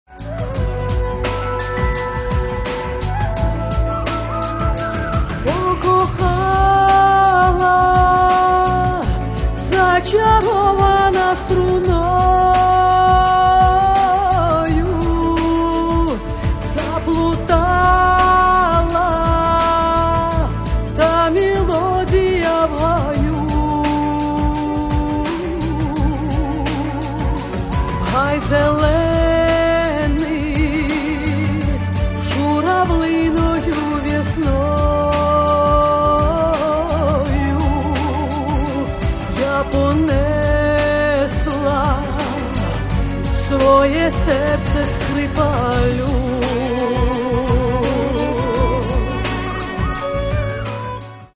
Каталог -> Естрада -> Поети та композитори